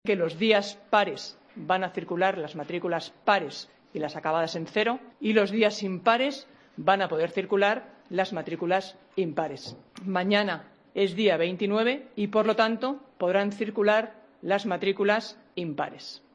AUDIO: La primera teniente de alcalde, Marta Higueras explica que el día 29-D podrán circular en el centro de Madrid las matrículas impares